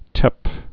(tĕp)